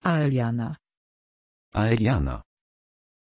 Pronunciation of Aeliana
pronunciation-aeliana-pl.mp3